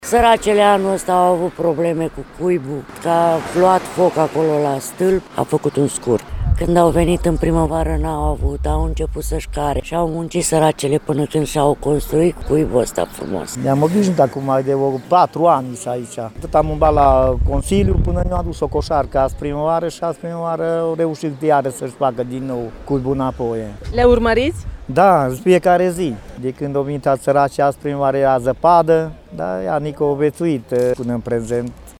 La Suseni, berzelor nu le-a fost ușor anul acesta. Au fost nevoite să-și construiască de două ori același cuib, spun sătenii: